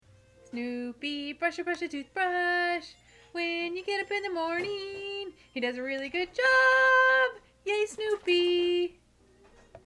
I like to sing my own version of the song.